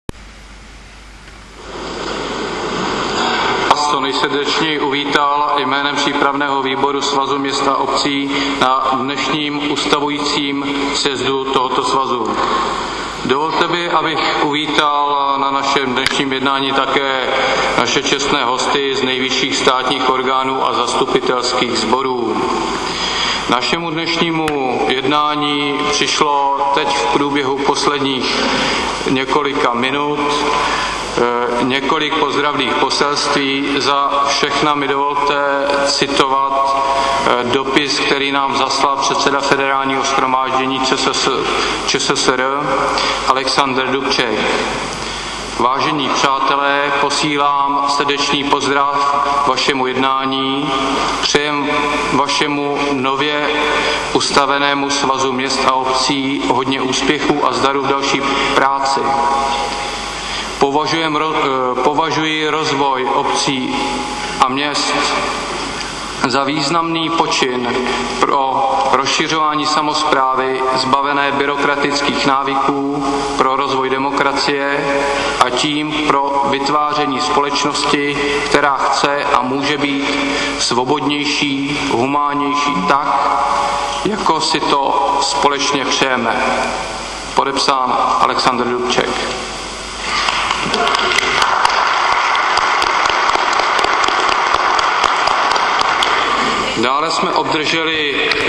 Audiozáznam úvodního uvítání účastníků